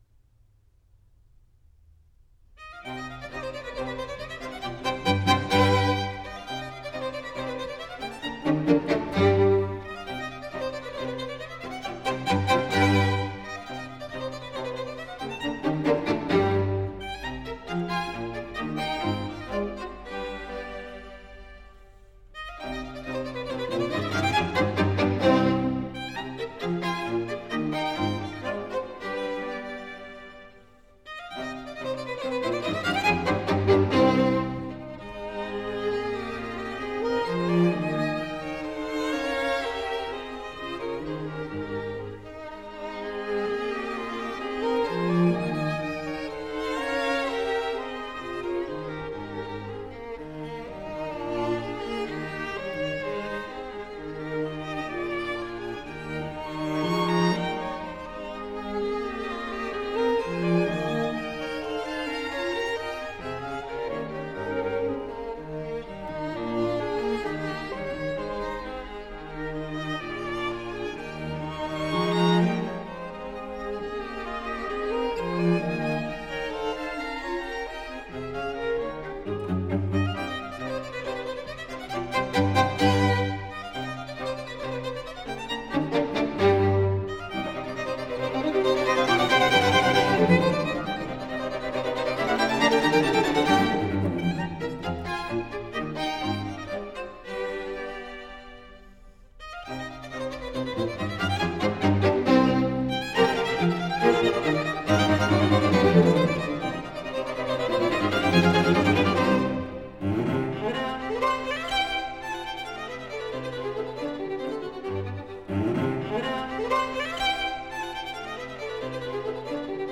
弦乐四重奏的组合看来简单，就两把小提琴，中提琴加大提琴，但历来为作曲家 们视为作曲技法的基本功。